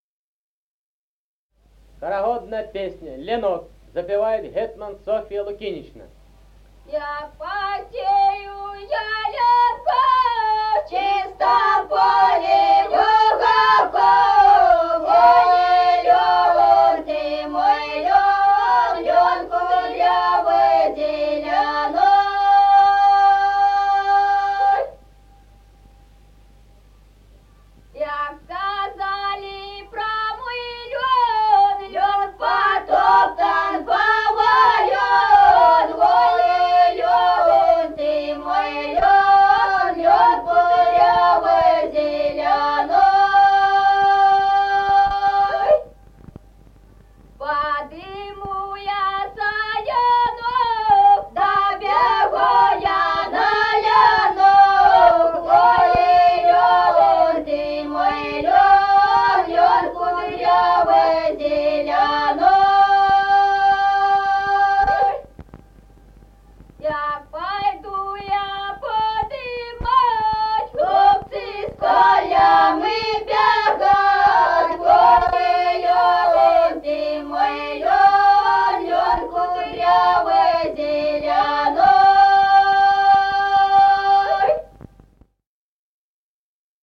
Народные песни Стародубского района «Як посею я ленку», карагодная.
подголосник
запев
с. Мишковка.